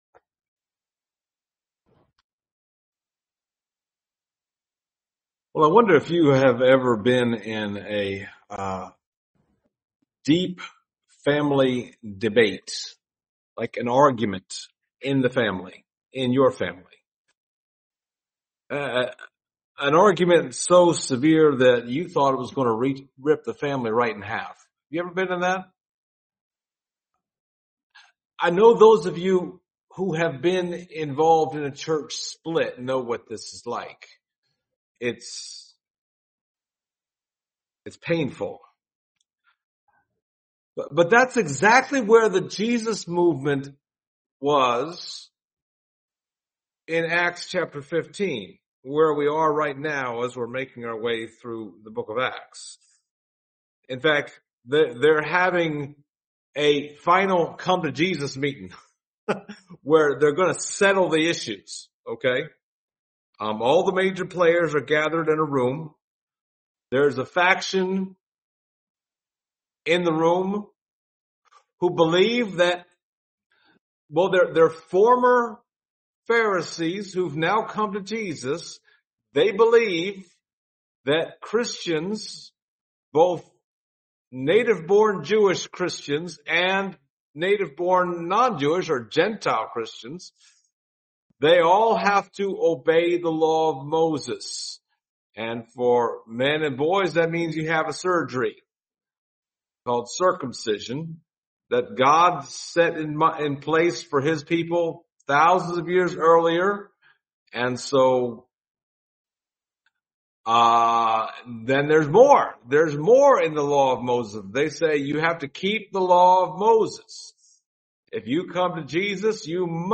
Passage: Acts 15:13-21 Service Type: Sunday Morning